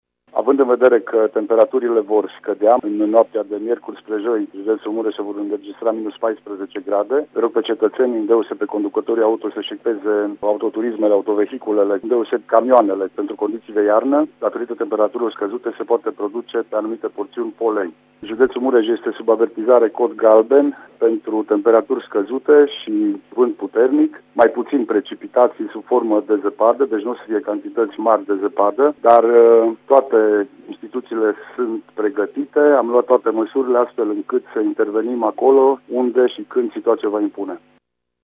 De la noapte vine gerul, iar județul Mureș nu va fi ocolit de acest val de frig. Autoritățile sunt pregătite să intervină, dacă va fi nevoie, mai spune prefectul Lucian Goga:
prefect-ger.mp3